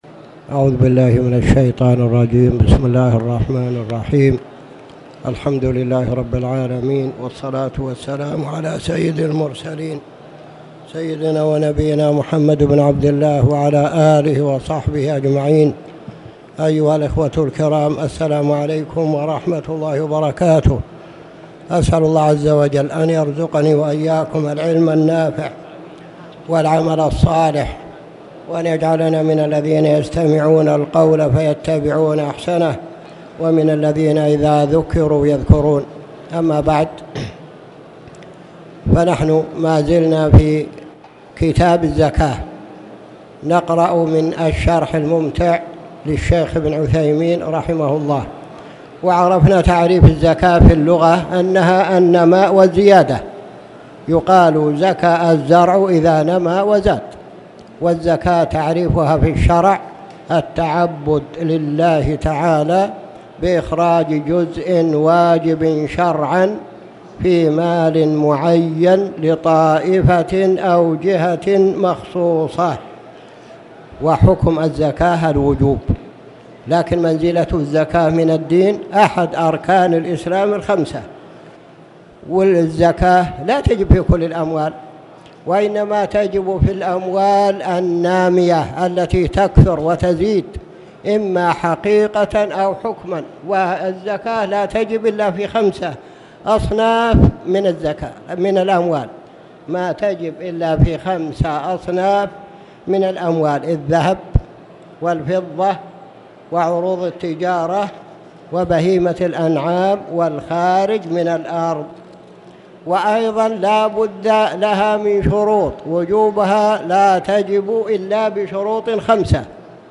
تاريخ النشر ٨ جمادى الآخرة ١٤٣٨ هـ المكان: المسجد الحرام الشيخ